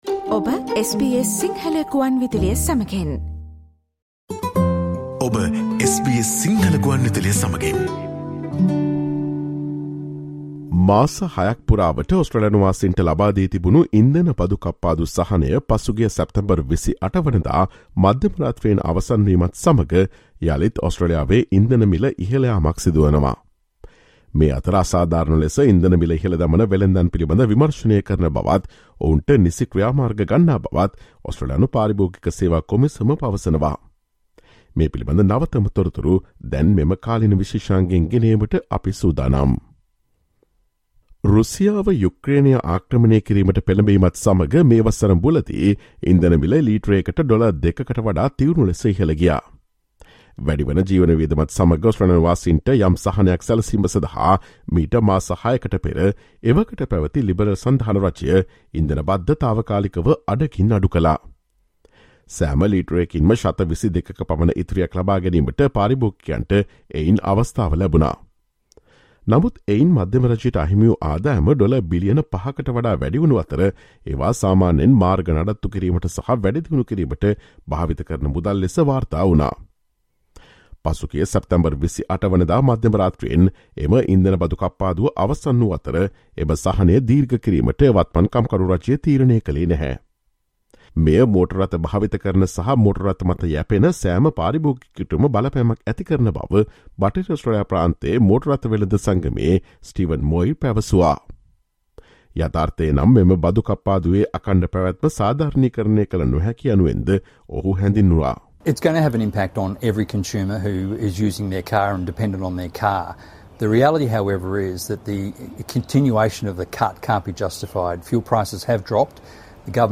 Drivers should brace for a petrol price hike in the coming days, with the return of the full fuel excise tax. Meanwhile, Australia's consumer regulator says it will keep a close eye on retailers for any signs of overcharging. Listen to the SBS Sinhala Radio's current affairs feature broadcast on Friday 30 September.